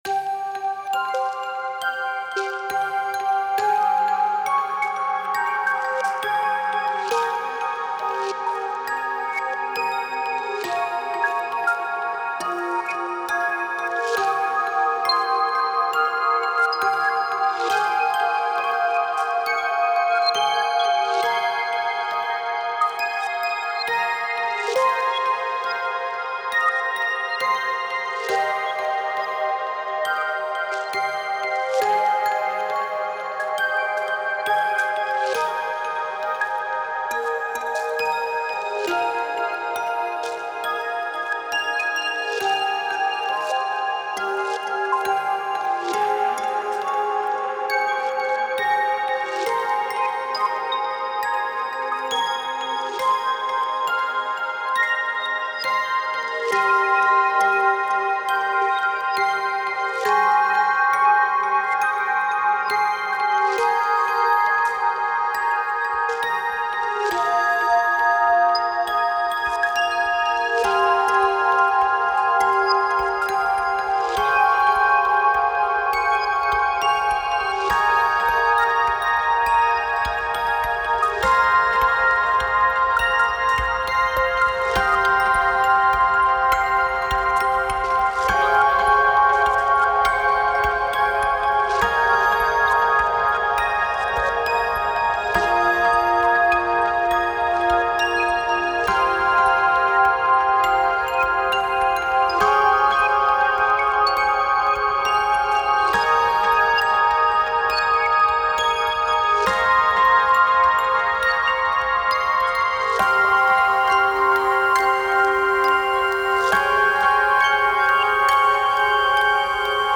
Chill アンビエント 不思議/ミステリアス 幻想的 音少なめ/シンプル コメント